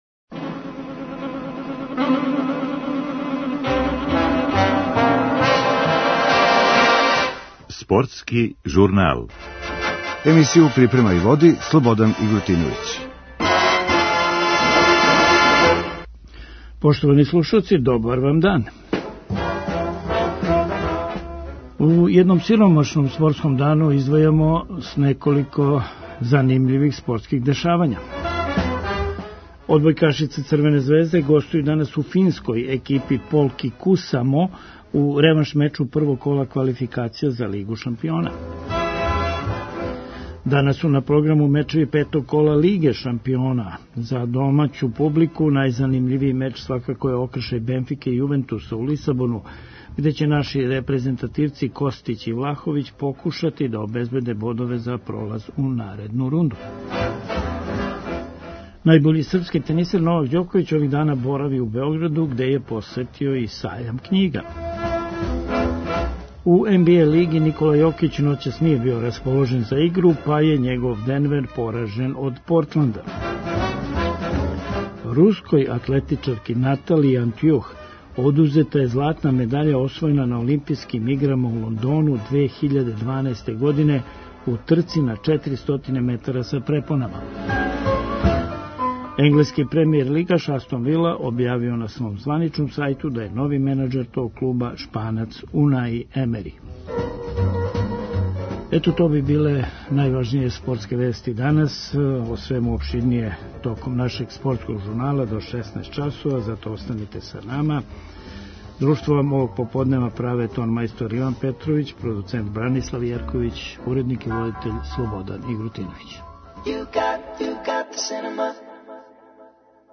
У емисији најављујемо утакмице фудбалске Лиге шампиона. Гост коментатор је